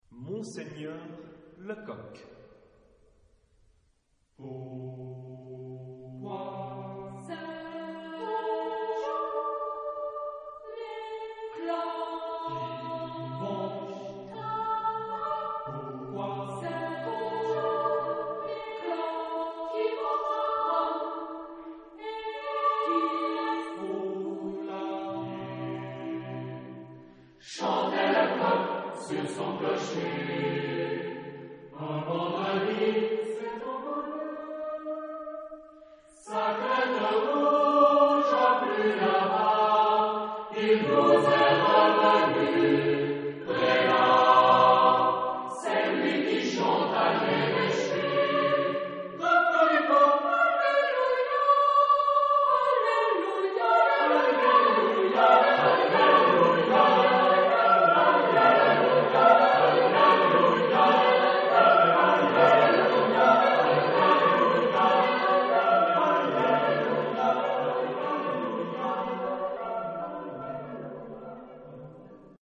Genre-Style-Form: Choral suite ; Partsong ; Poem ; Secular
Mood of the piece: funky ; humorous
Type of Choir: SSATB  (5 mixed voices )